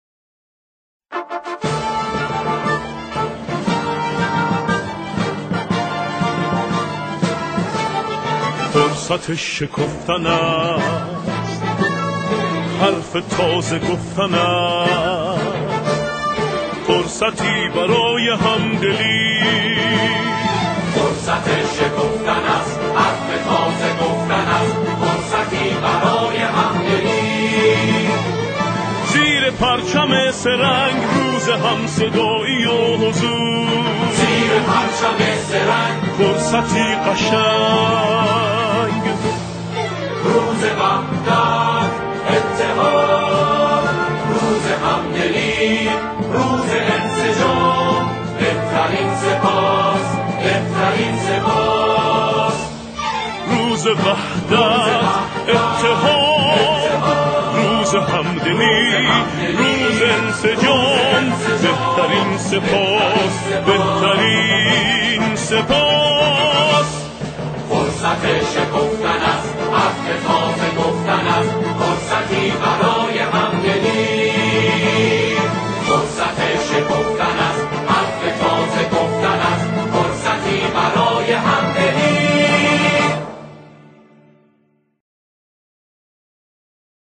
آنها در این قطعه، شعری را درباره انتخابات همخوانی می‌کنند.